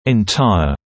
[ɪn’taɪə][ин’тайэ]весь, целый, полный